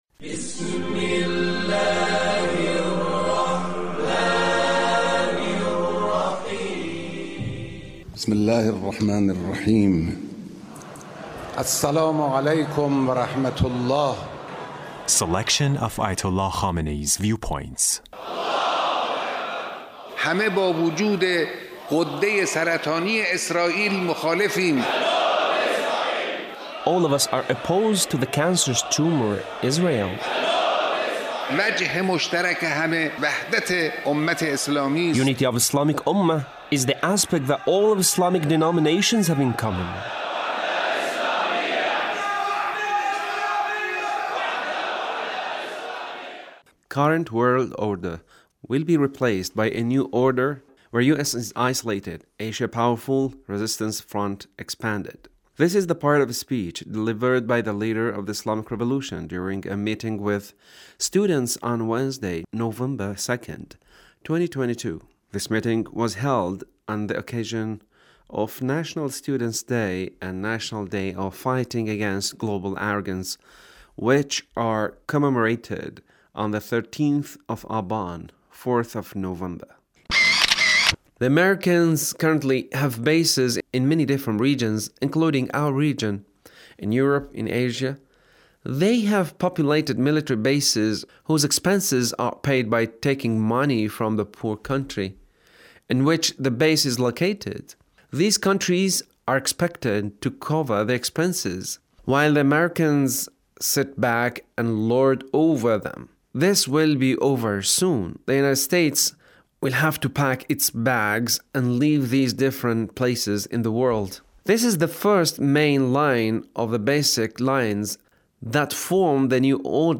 Leader's Speech on 13th of Aban